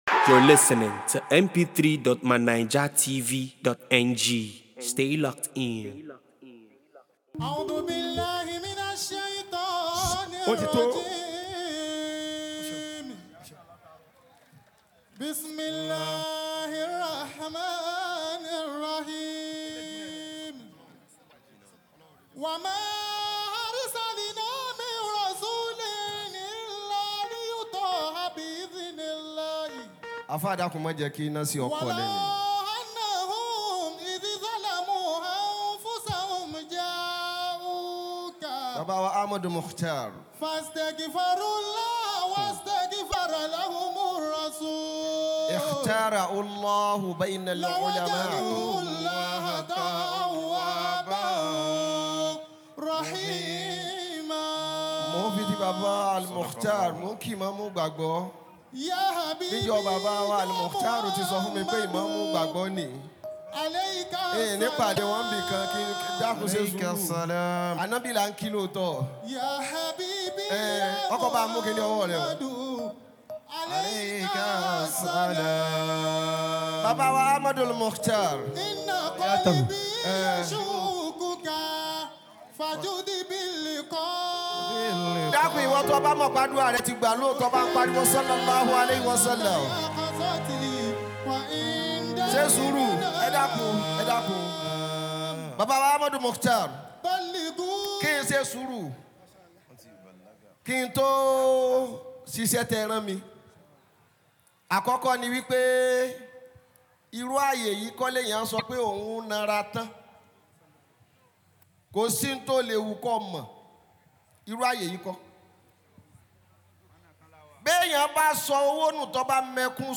at Muhammad Rosulullah Global Family 2025 Burdah Day